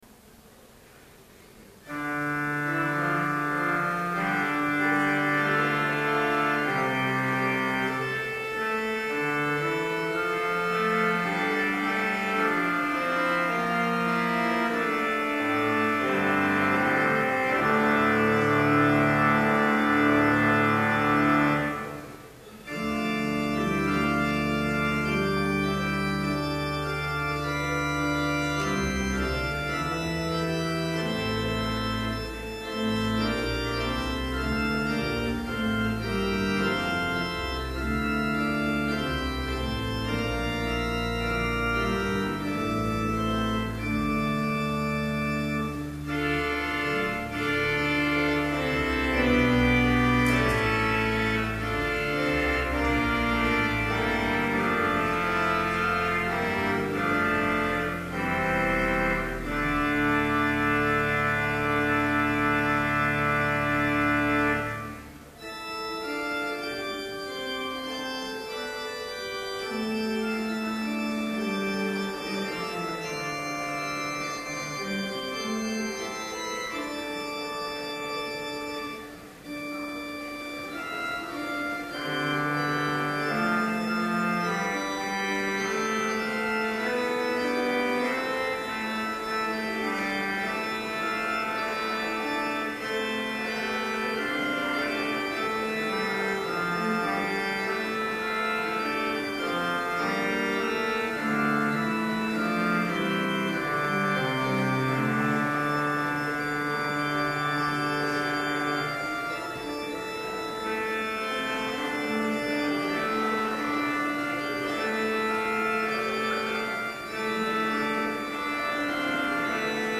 Complete service audio for Chapel - April 27, 2012